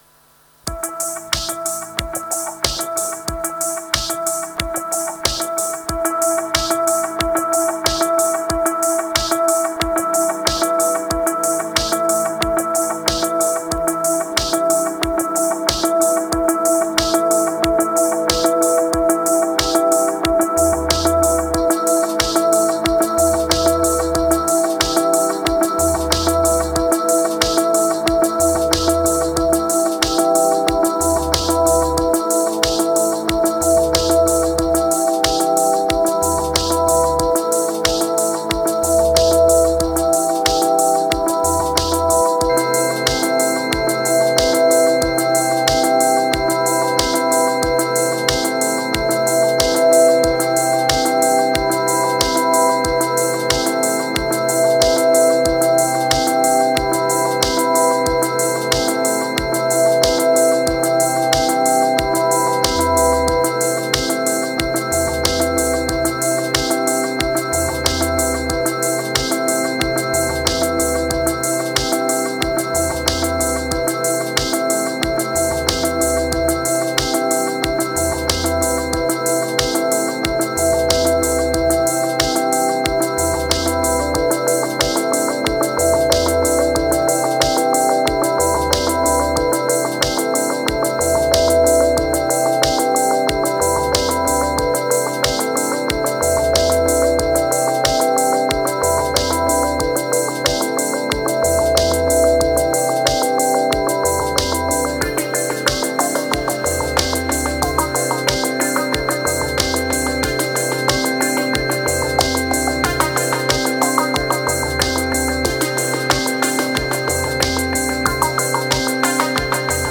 Ambient beats, MPC X. Tired condition, lower moods.